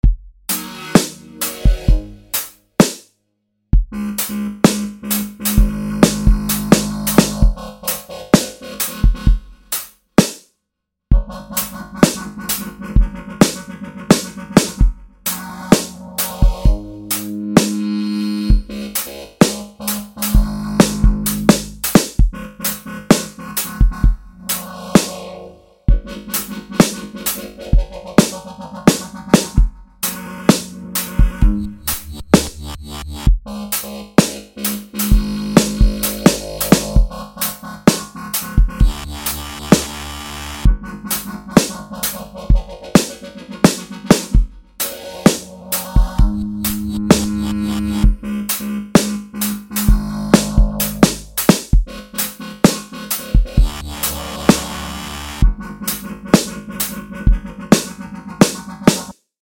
Welche Richtung ist das, was ich da mit Zampler gebaut habe?